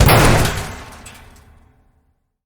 lockerSmash.ogg